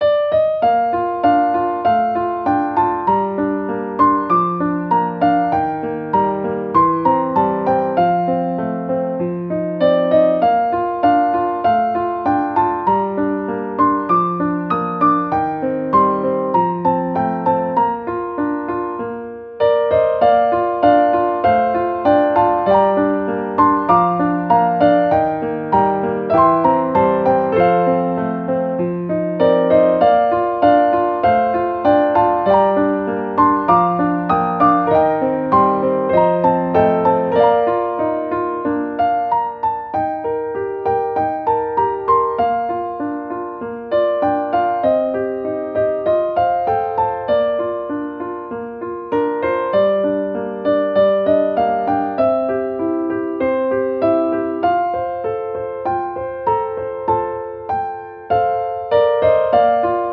ogg(L) 楽譜 癒し ゆったり 日常
爽やかな自然風景に合う楽曲。